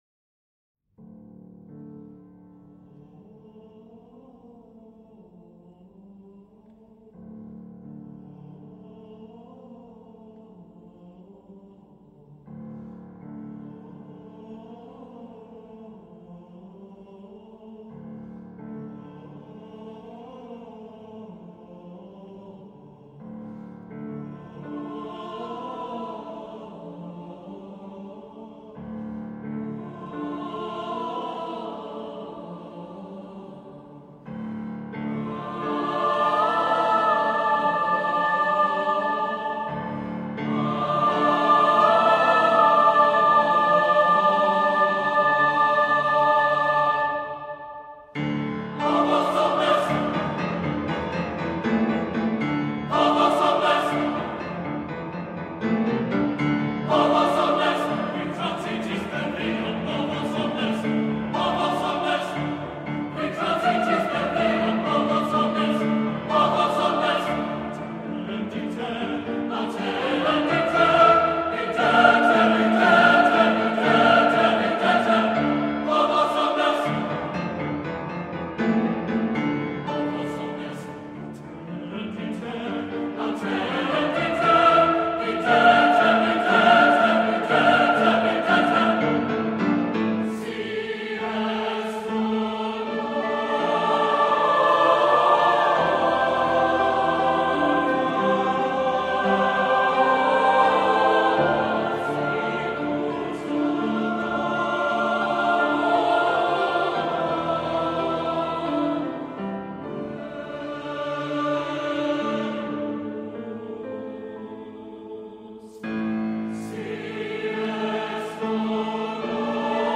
Voicing: SATB